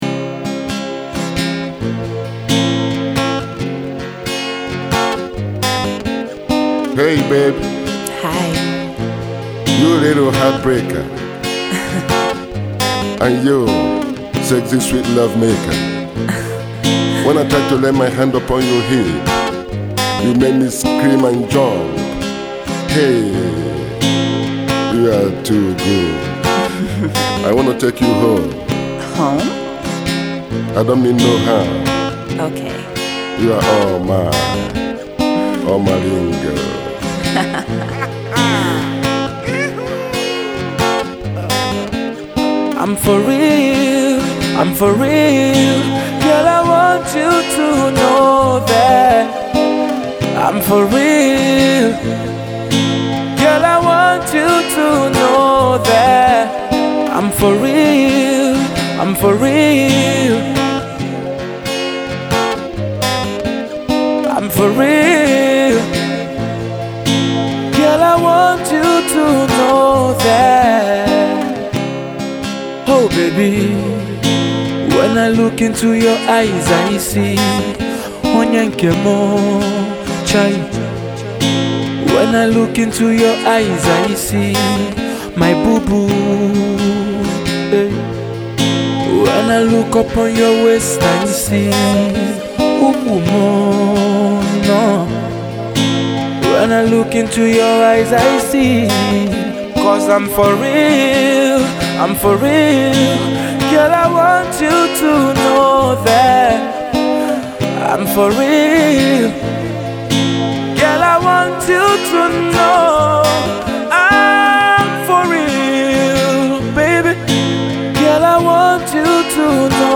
a sweet romantic present to serenade lovers
in an acoustic style